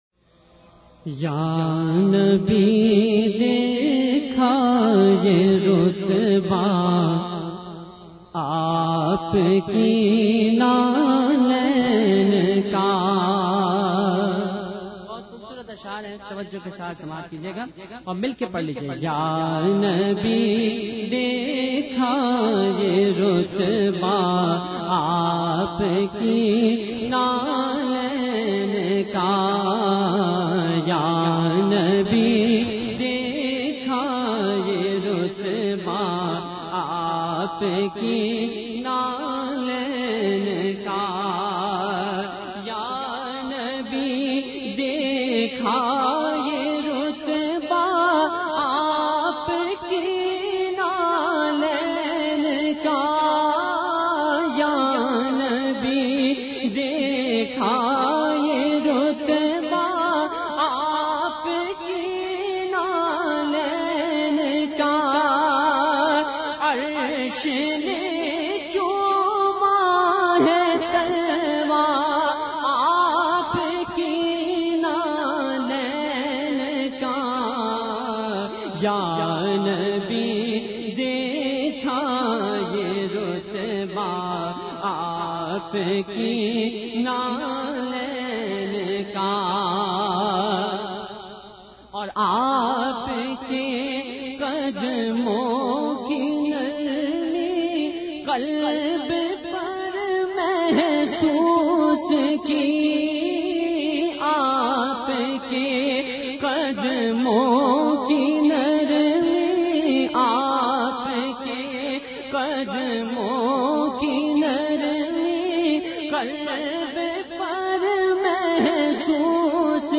Category : Naat | Language : Urdu